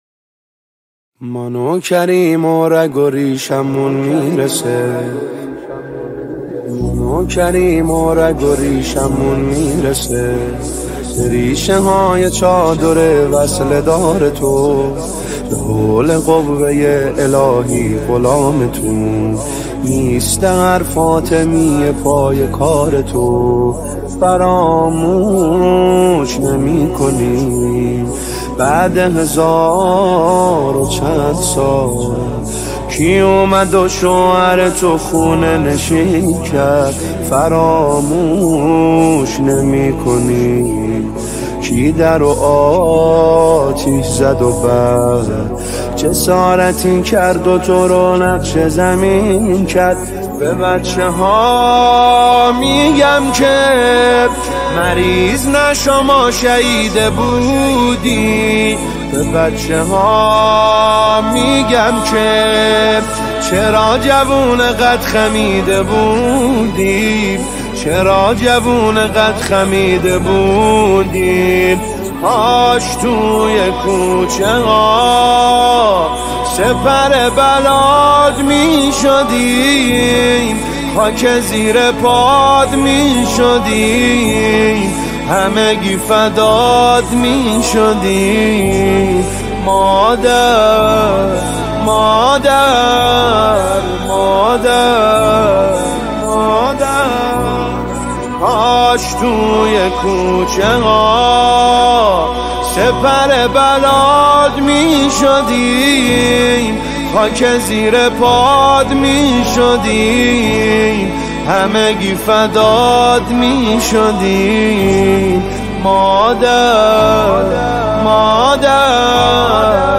ایام فاطمیه رو به همه شما عزیزان تسلیت میگم بهتره تو این ایام عزا،دل وروحمون رو بایه مداحی زیبا متوسل کنیم پس باهم مداحی زیبای قرار با مادر رو بشنویم (گلهای انتظار)